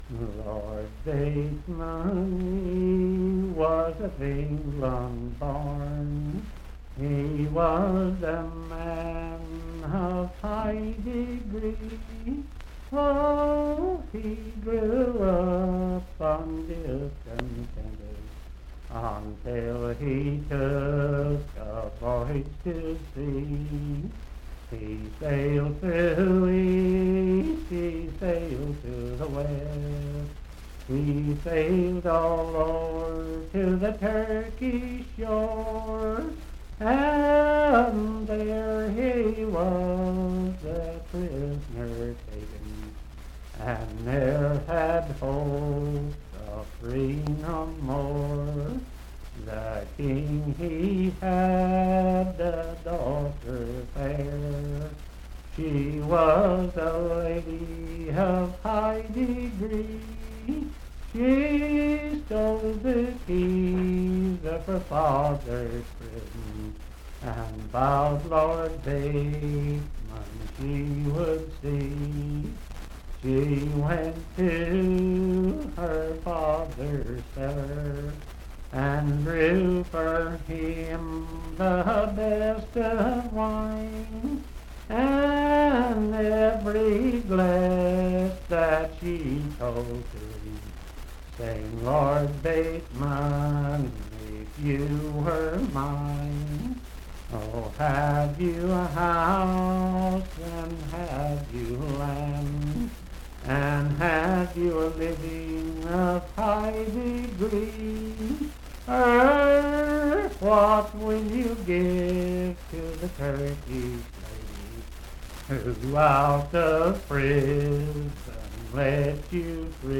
Unaccompanied vocal music
in Dryfork, WV
Verse-refrain 14(4).
Voice (sung)
Randolph County (W. Va.)